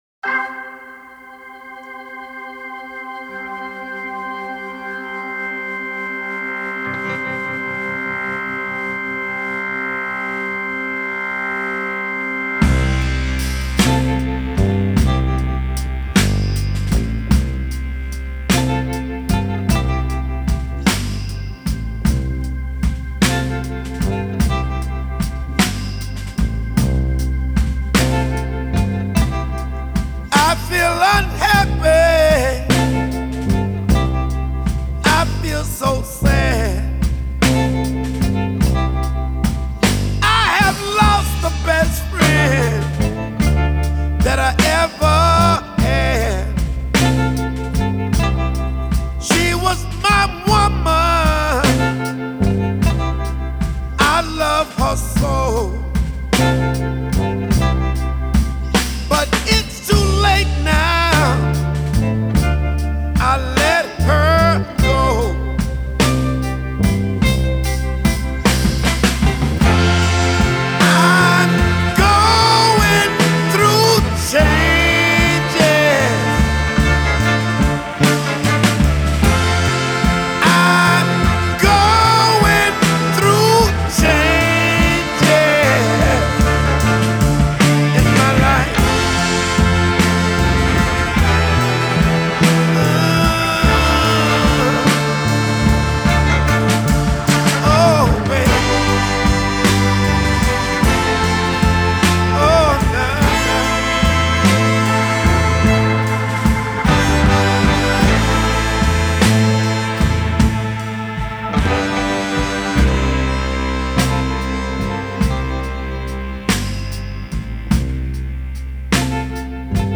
Genero: Blues